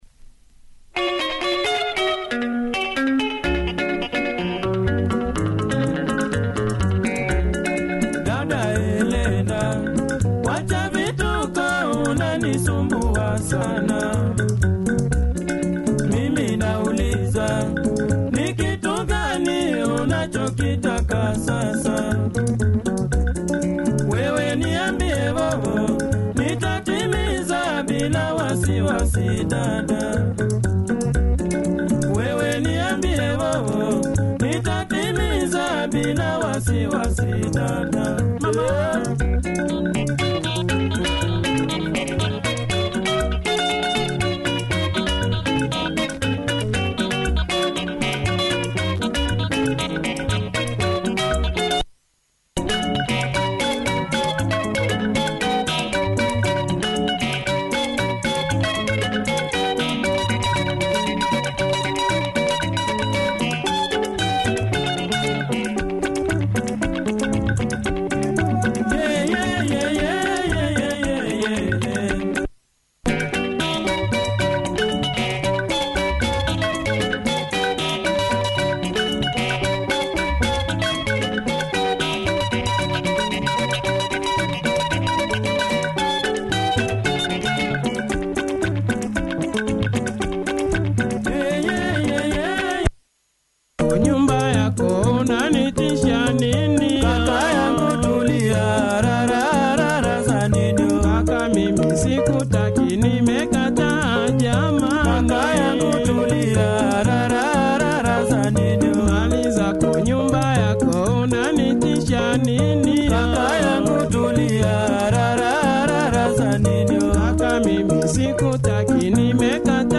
sweet Tanzania vibe